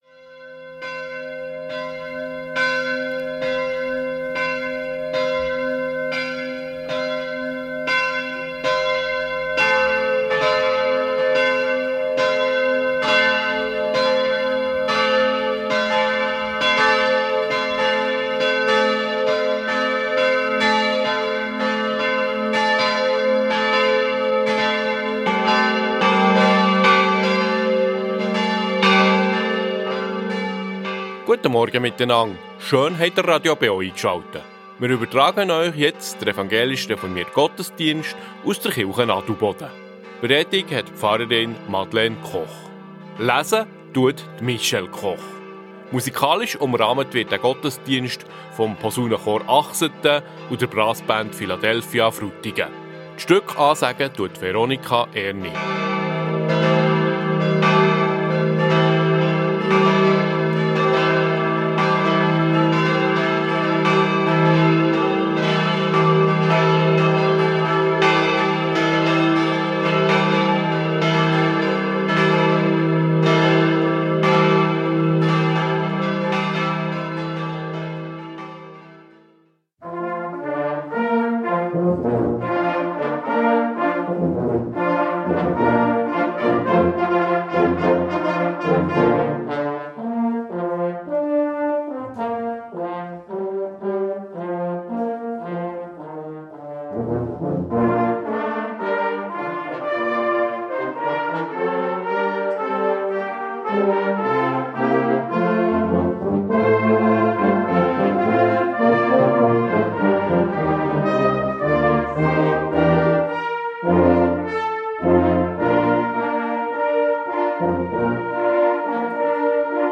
Reformierte Kirche Adelboden ~ Gottesdienst auf Radio BeO Podcast